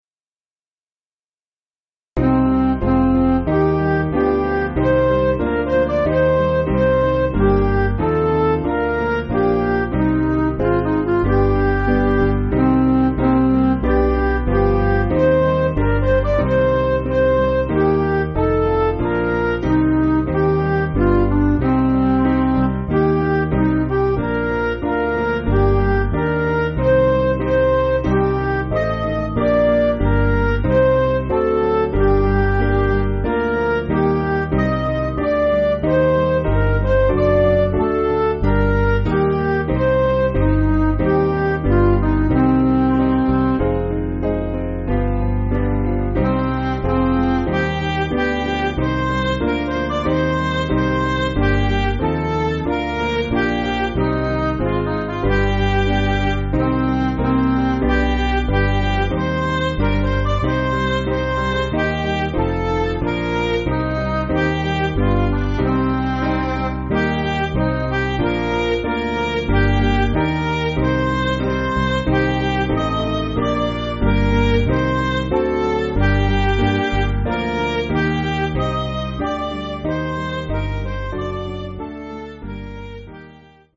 Piano & Instrumental
(CM)   4/Cm